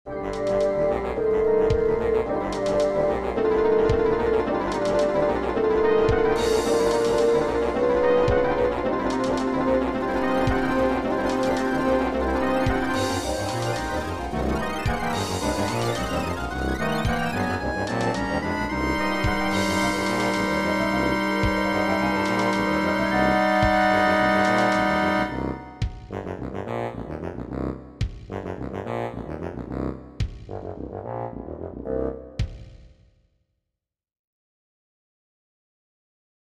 These are created in Finale, and saved as MIDI files (and also MP3 files).
The MP3 file will give you an idea of what I intended it to sound like: how the MIDI file sounds will depend a lot on your system.
You're right, it does sound a bit ... agitated.